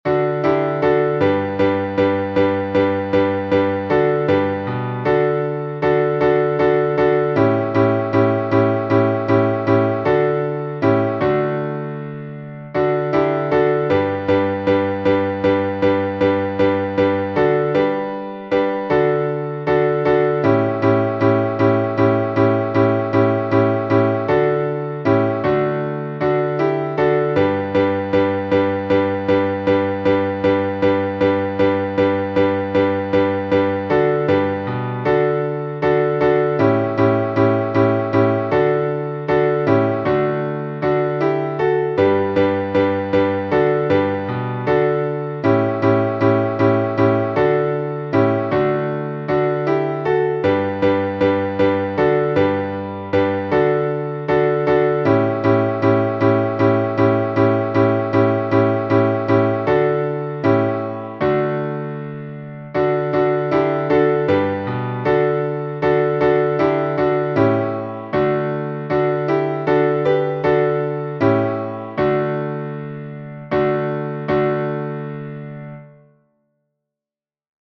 Киевский распев, глас 8